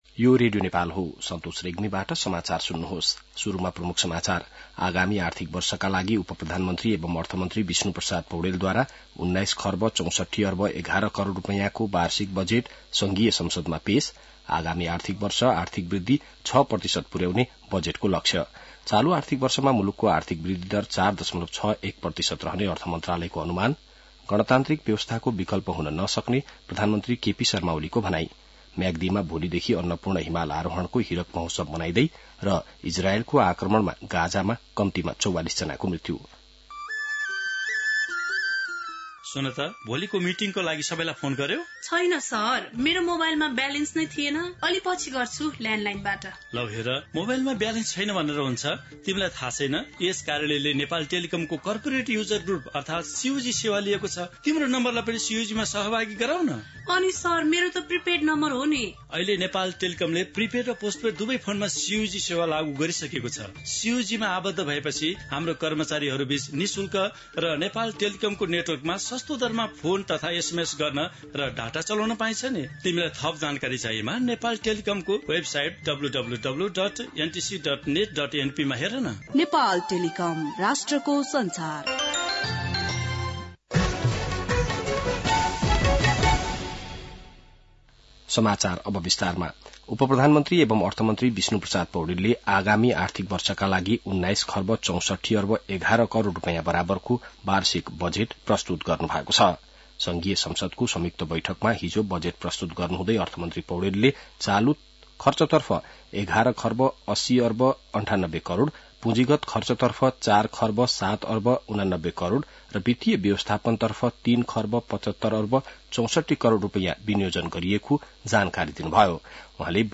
बिहान ७ बजेको नेपाली समाचार : १६ जेठ , २०८२